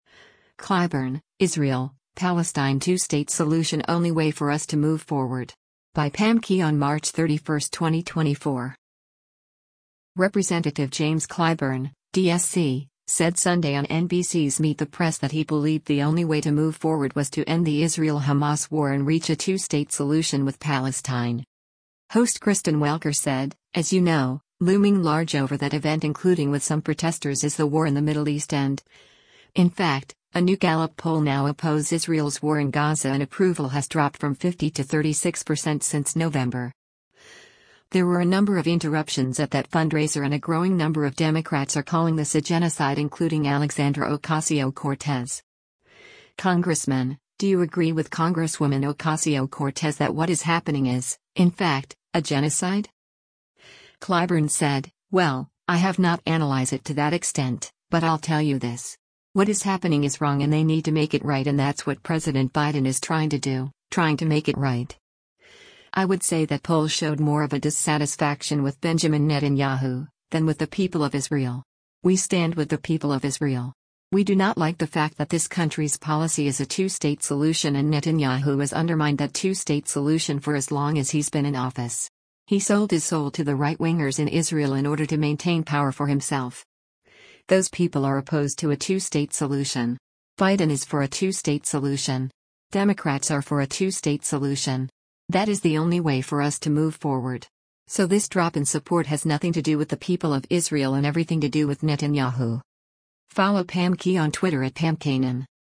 Representative James Clyburn (D-SC) said Sunday on NBC’s “Meet the Press” that he believed the only way to move forward was to end the Israel-Hamas war and reach a two-state solution with Palestine.